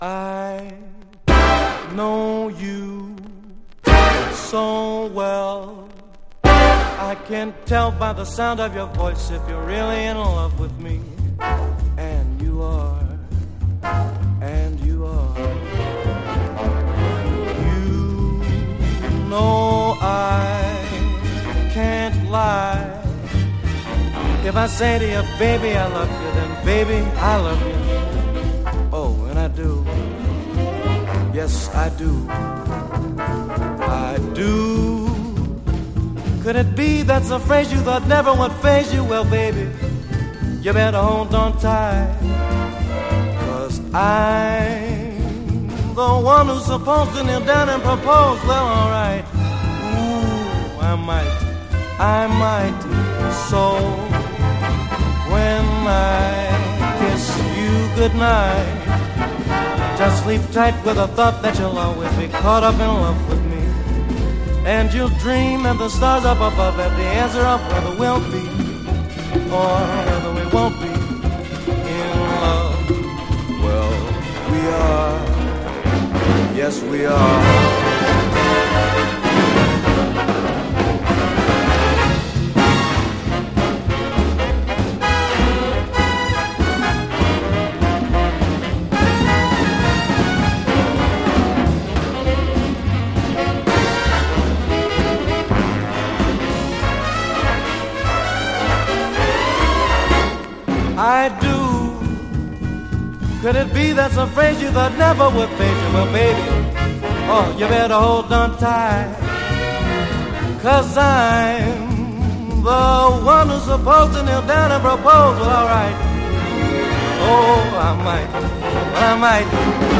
小粋な80’S〜90’Sスウィング人気曲